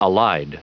Prononciation du mot allied en anglais (fichier audio)